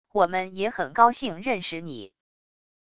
我们也很高兴认识你wav音频生成系统WAV Audio Player